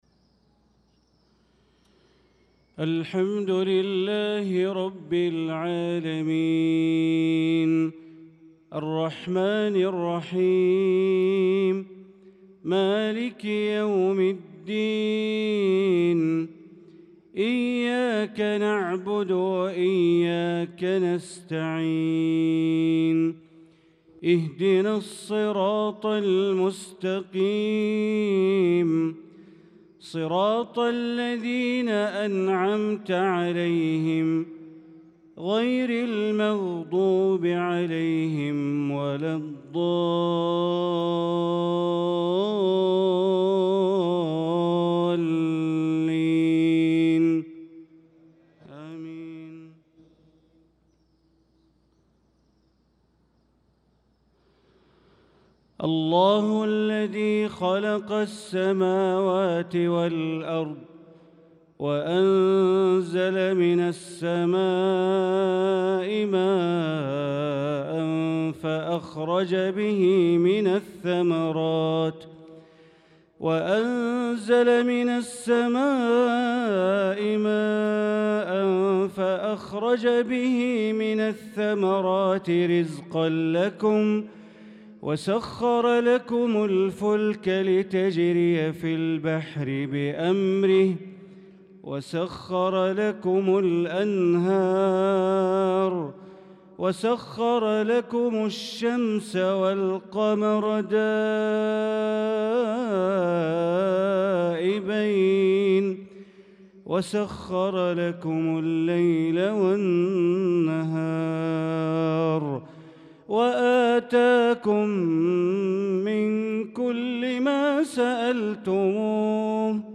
صلاة المغرب للقارئ بندر بليلة 27 شوال 1445 هـ
تِلَاوَات الْحَرَمَيْن .